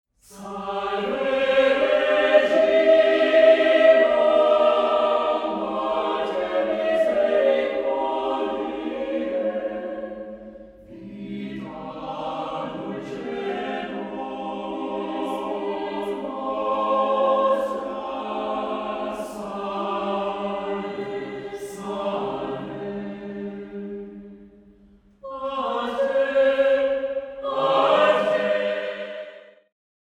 A variable-sized choir ranging from 16 to 32 singers
chamber choir
soloist